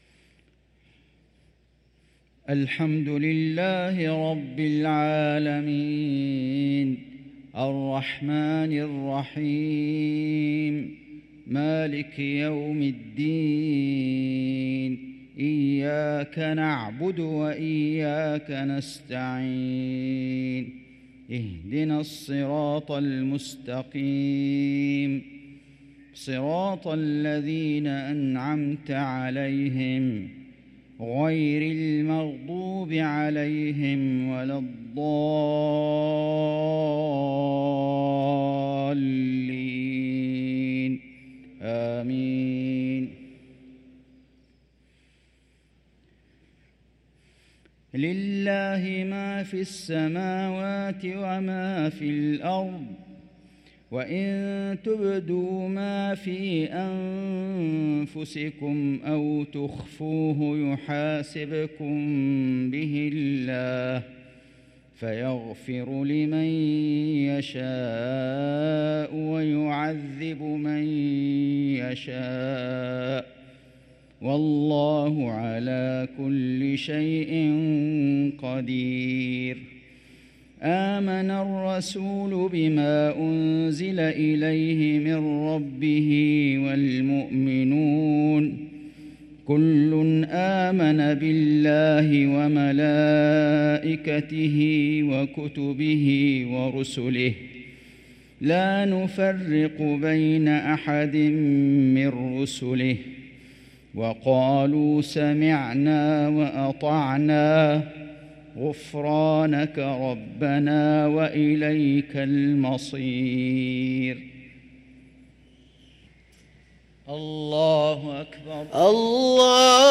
صلاة المغرب للقارئ فيصل غزاوي 27 ربيع الأول 1445 هـ
تِلَاوَات الْحَرَمَيْن .